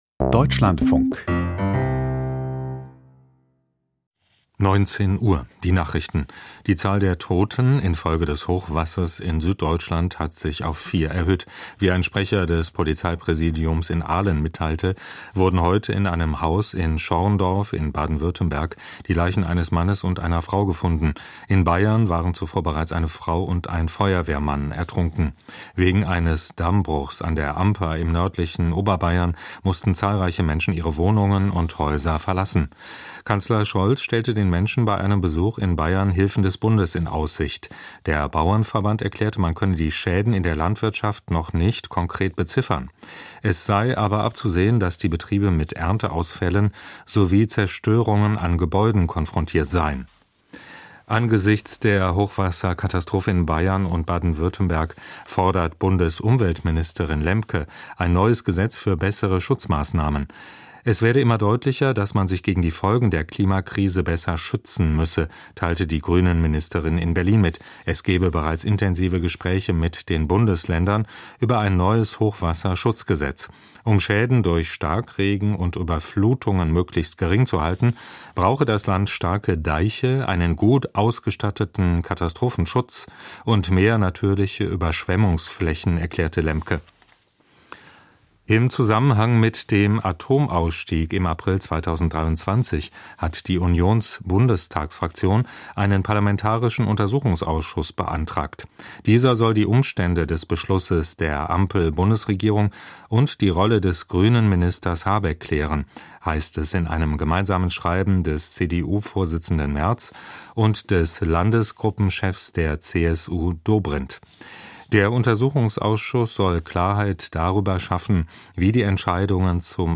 Kommentar zur WDR-Umfrage - Der DFB versagt selbst bei einem Statement gegen Rassismus - 03.06.2024